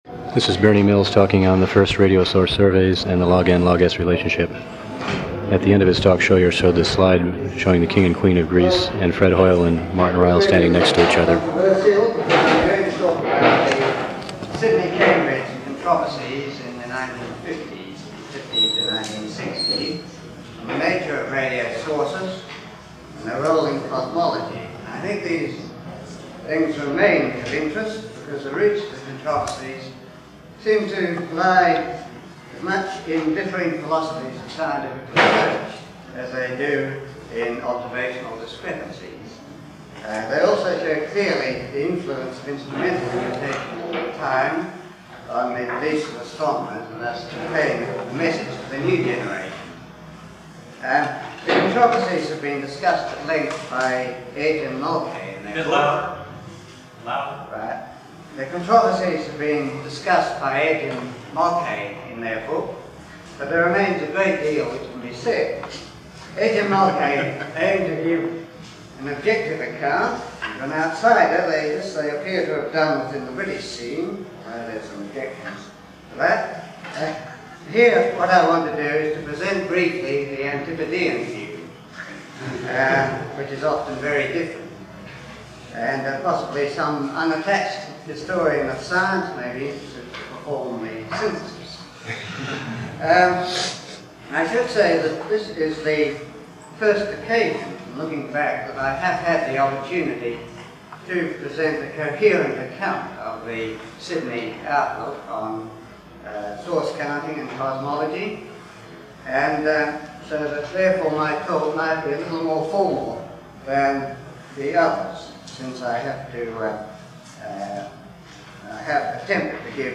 Talk given at XVIIIth General Assembly of the International Astronomical Union, 1982, Patras, Greece